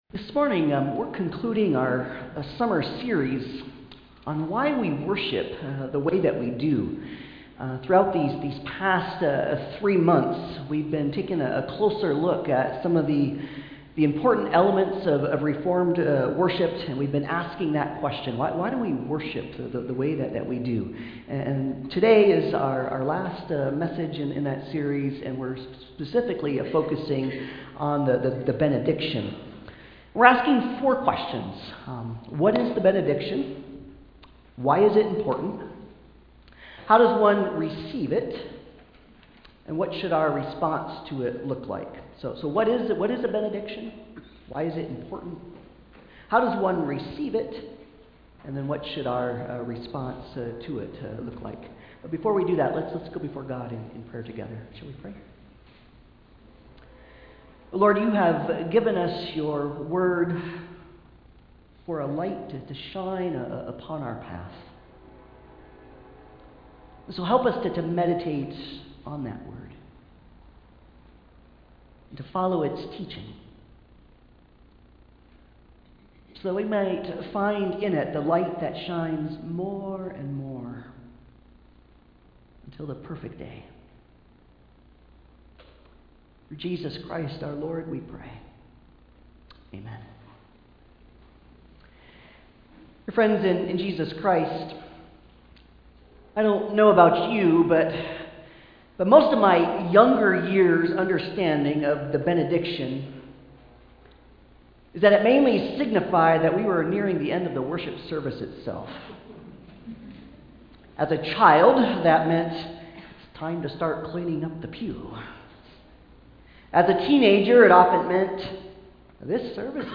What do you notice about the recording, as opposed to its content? Number 6:22-27 Service Type: Sunday Service « Professing our Faith